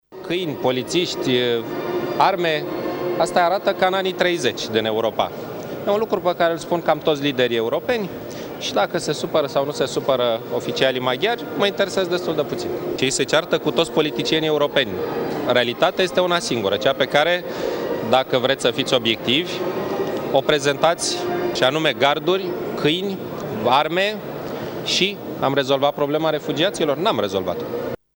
Victor Ponta a declarat că se alătură tuturor liderilor europeni care sancționează o atitudine rușinoasă pentru Europa din partea autoităților dintr-o țară membră a UE, Ungaria: